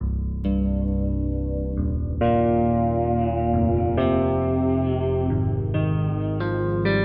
Emotionless_Cm_136.wav